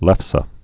(lĕfsə)